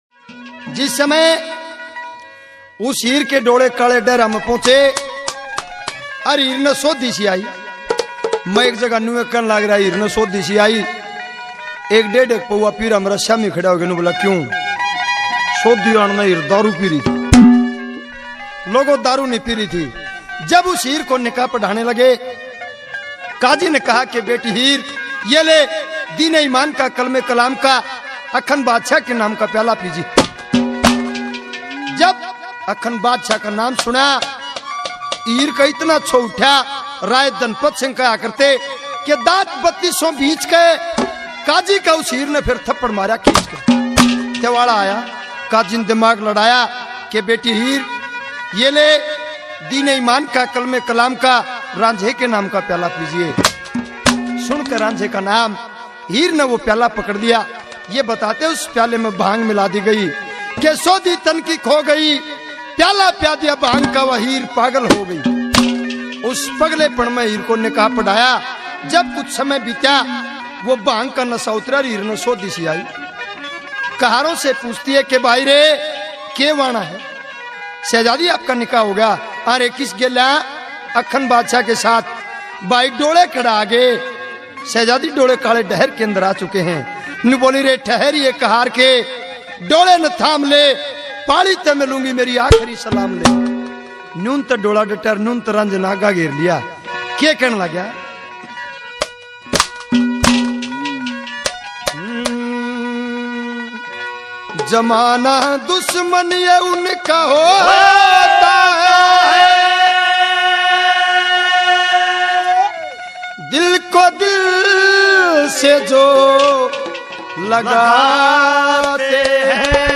Hit Ragniya